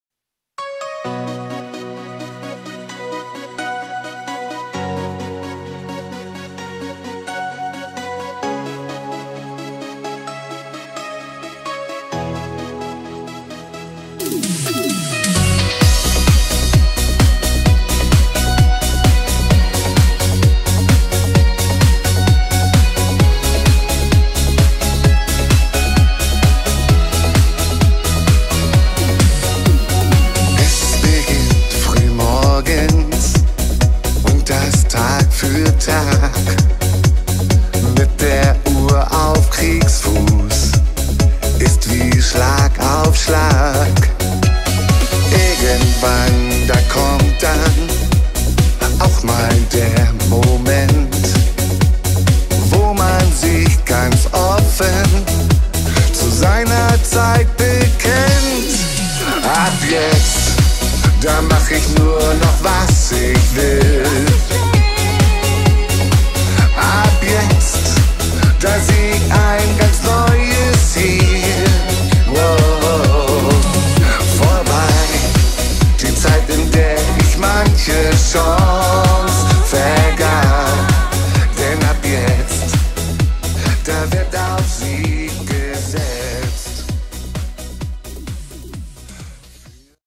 Genre: Schlager